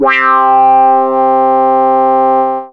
RESO SYNTH.wav